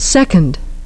The great majority of two-syllable words in English are stressed on the first syllable, for example, English <)),